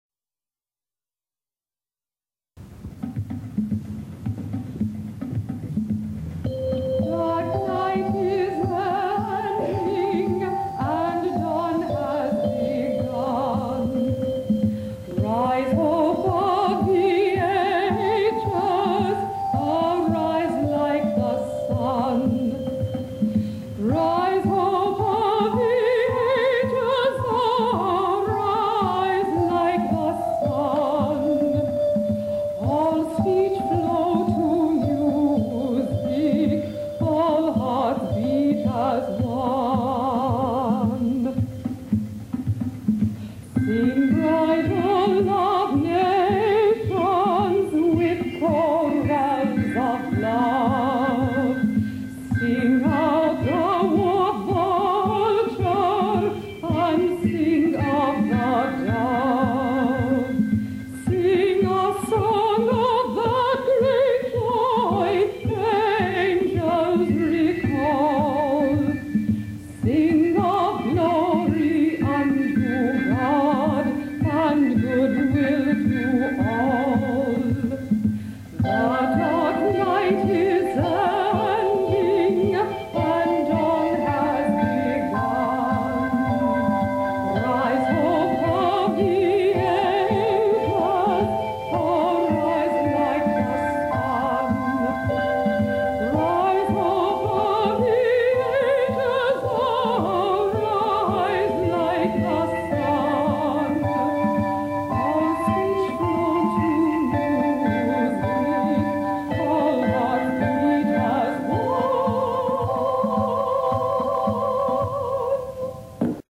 many from live performances.
keyboard 12.